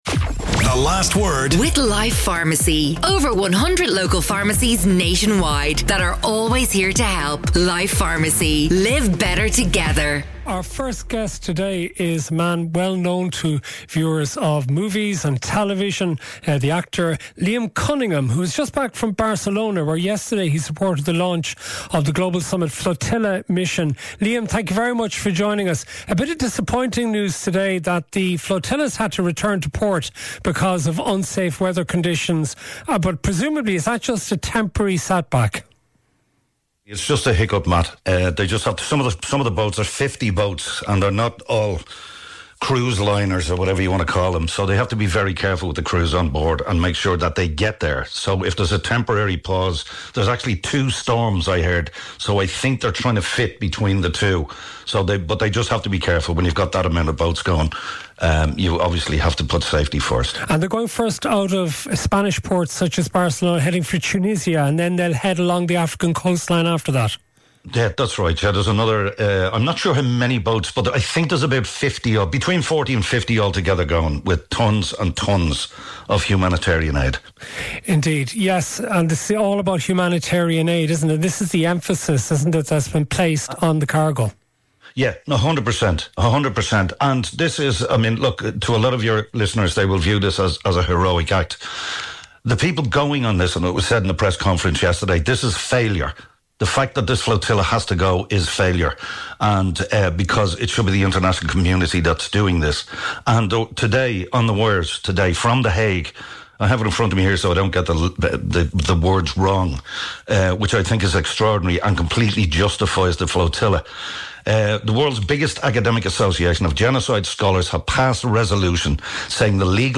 On Ireland's most cutting edge current affairs show, Matt and his guests provide a running stream of intelligent opinions and heated debates on the issues that matter most to Irish listeners.